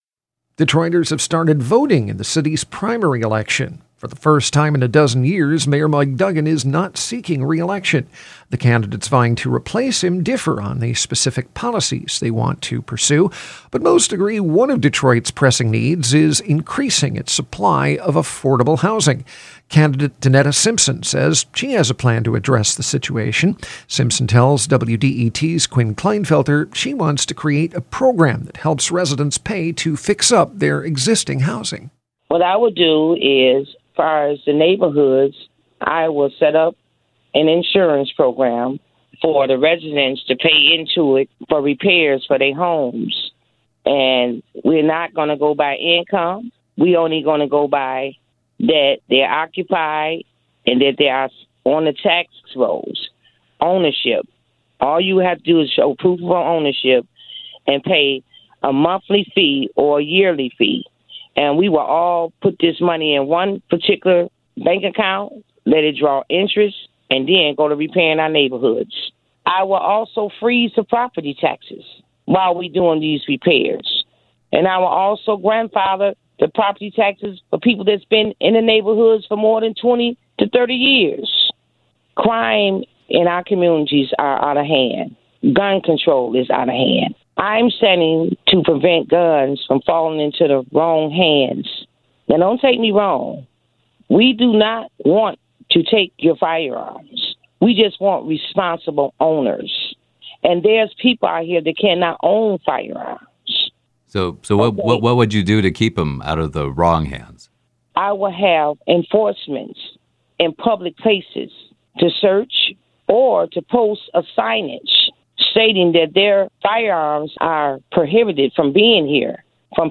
The following interview has been edited for clarity and length.